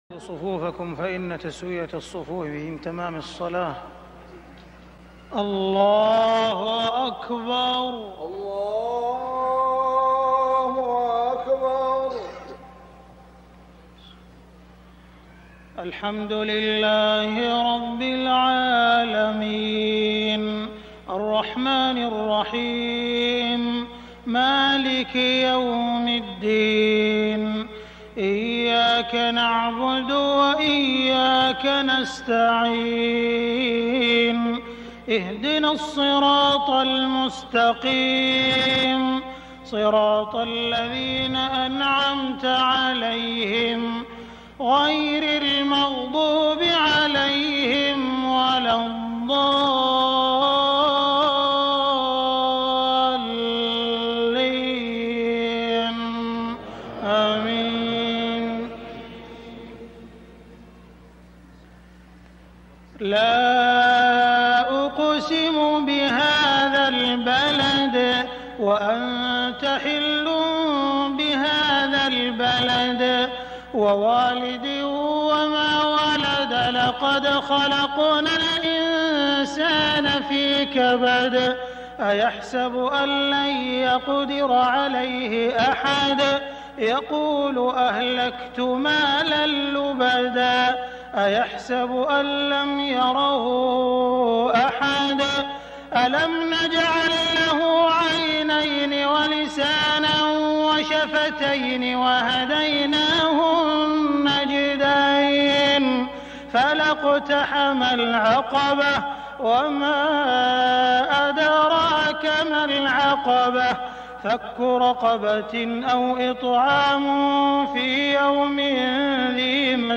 صلاة العشاء من المسجد الحرام 1421هـ سورتي البلد و التين > 1421 🕋 > الفروض - تلاوات الحرمين